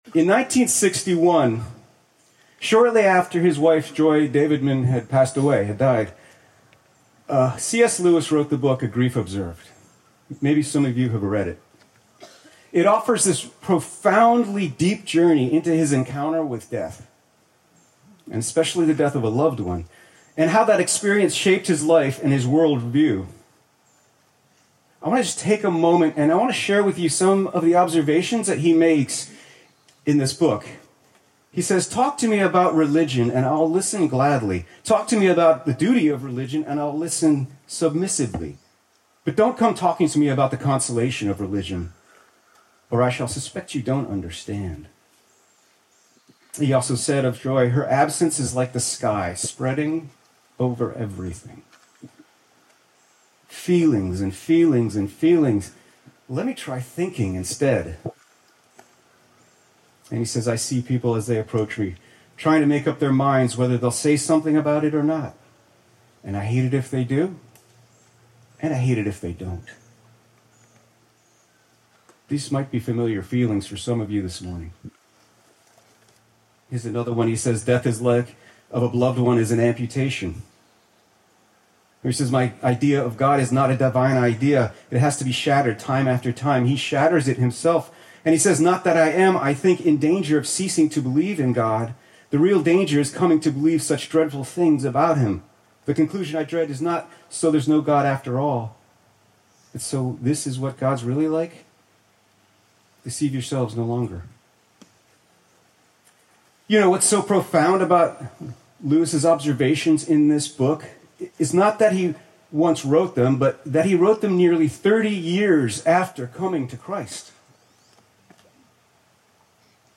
Easter Sunrise Service 2025